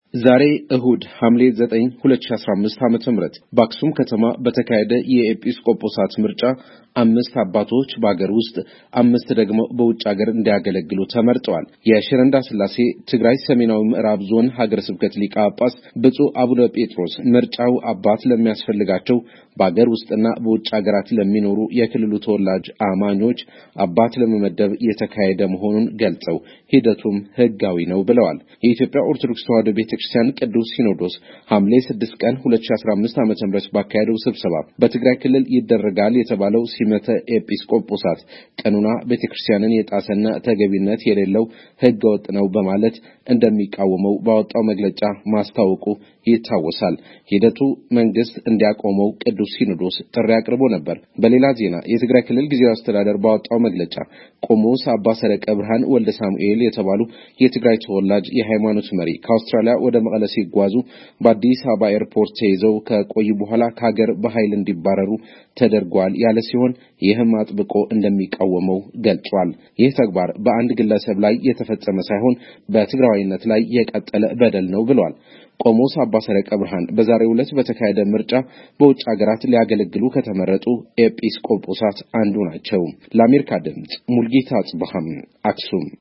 ከአክሱም የተያያዘውን ዘገባ ልኳል።